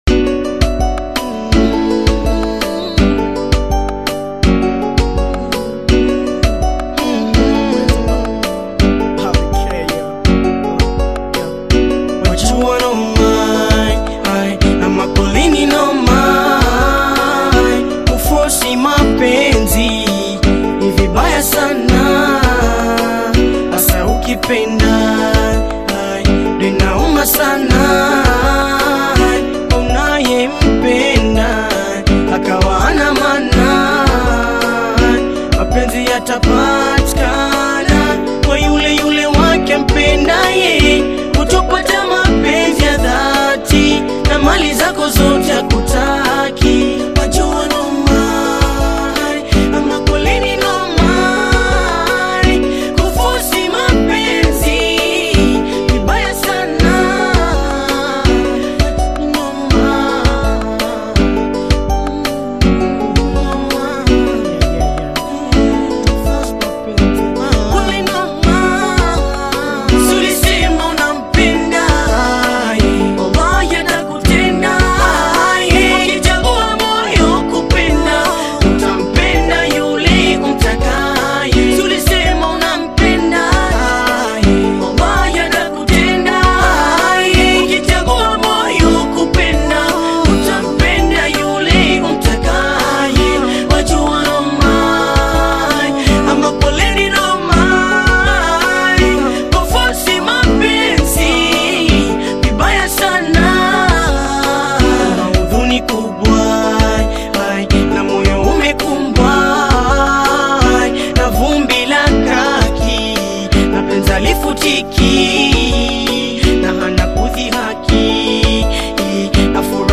emotional Bongo Fleva love song